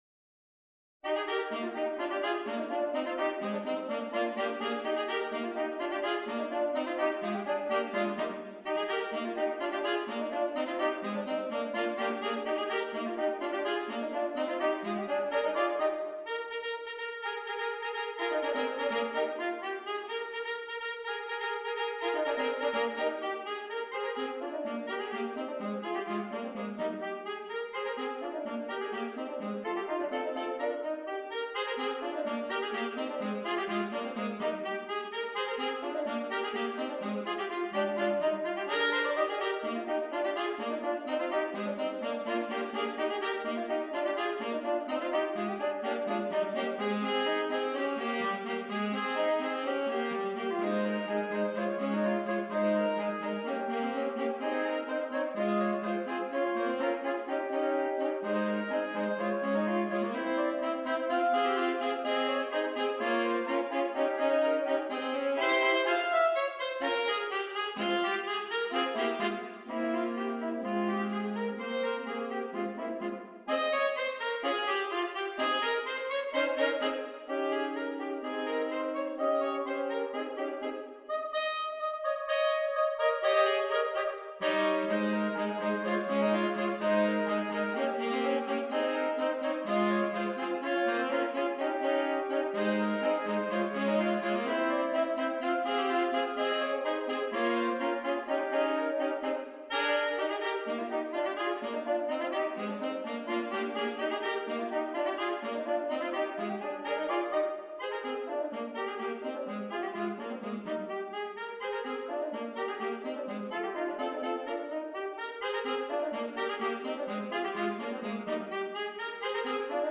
3 Alto Saxophones
a light-hearted work